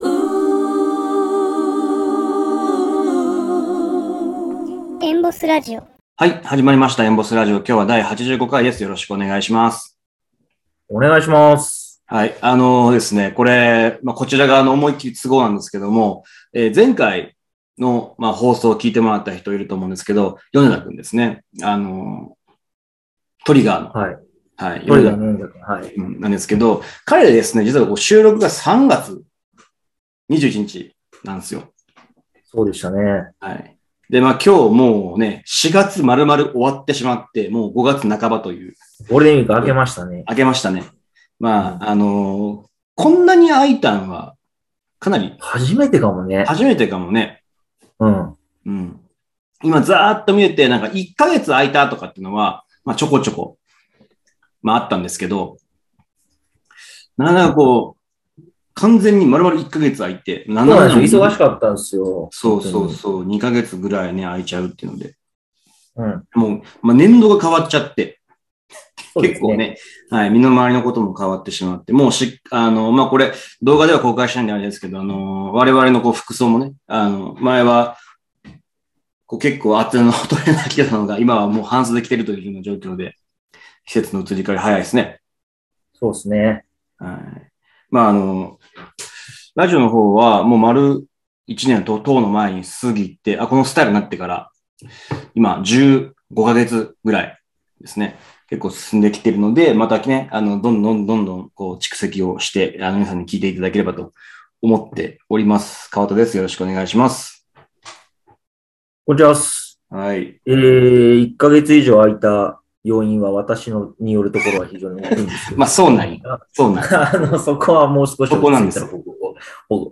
本日のゲスト